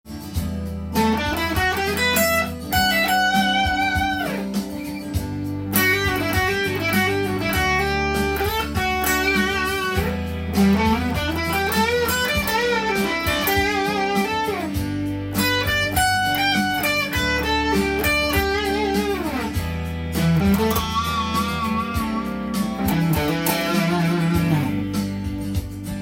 以上のオリアンティ―フレーズを使ってカラオケに合わせて弾いてみました